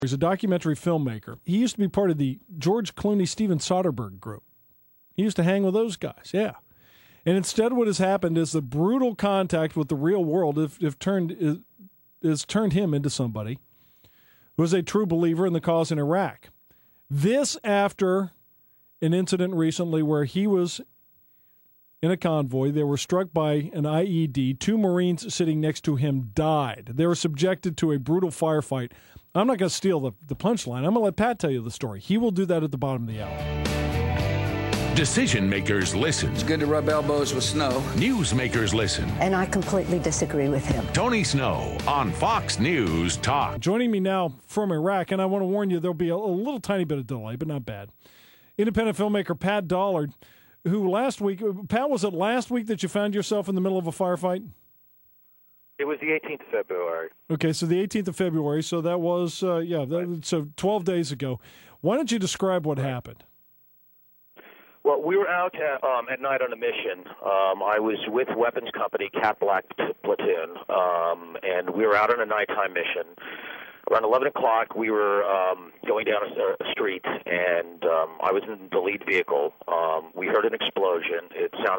Interview By White House Press Secretary Tony Snow (MP3 audio file)
They were done via phone, live from Ramadi.